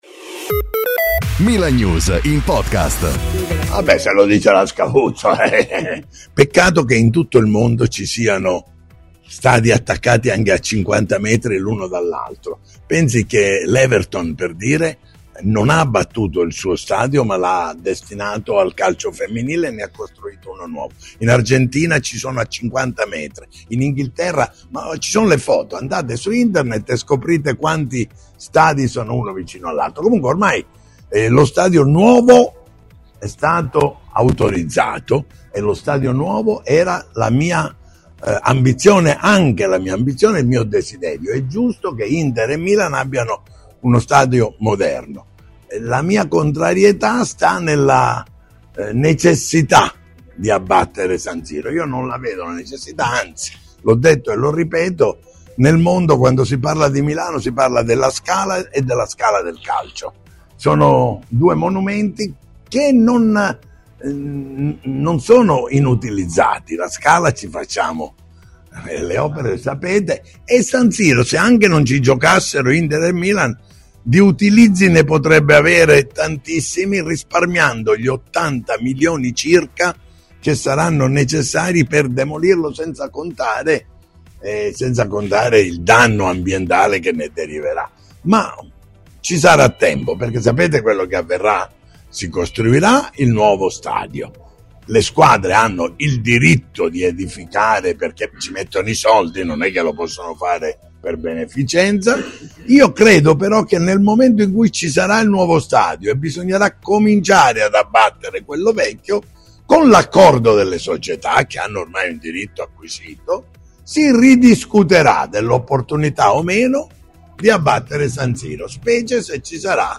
Ignazio La Russa, attuale Presidente del Senato, è intervenuto oggi alla conferenza di presentazione della Milano International FICTS Fest 2025 e ha parlato successivamente davanti ai giornalisti presenti sul tema stadio San Siro, nella settimana della delibera che ha concesso la possibilità di acquistare l'area ai due club.